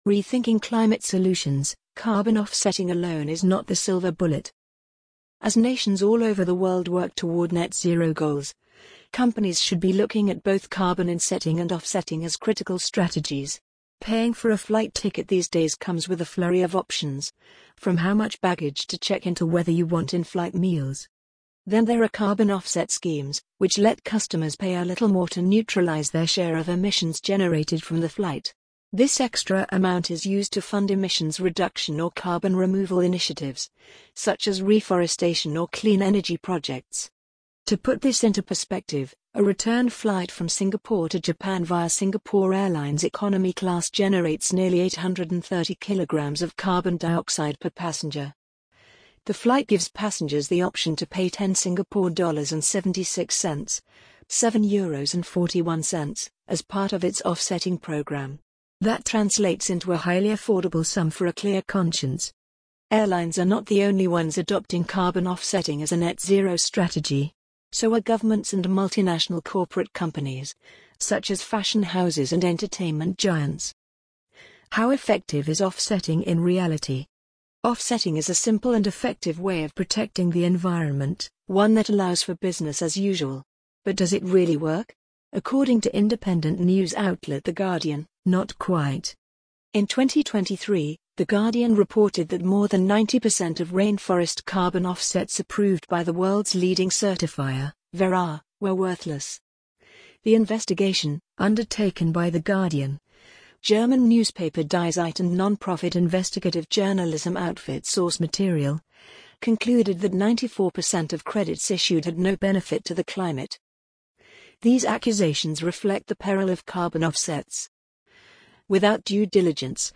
amazon_polly_44418.mp3